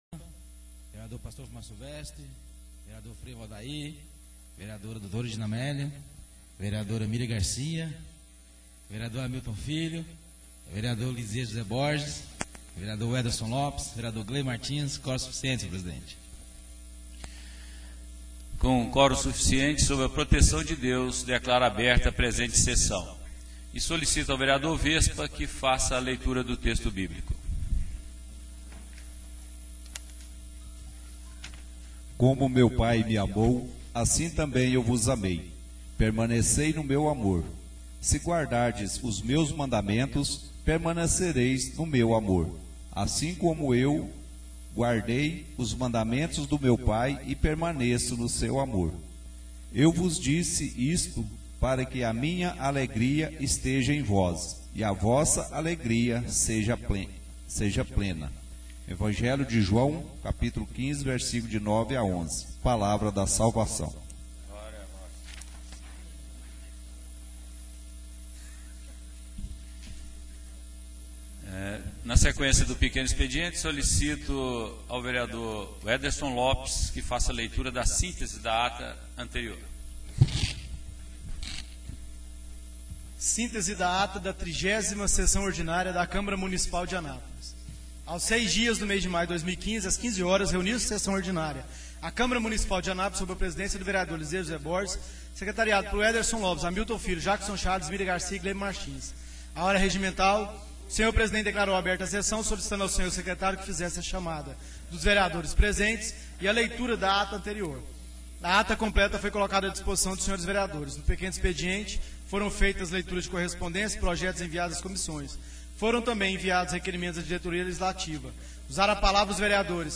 Sessão Ordinária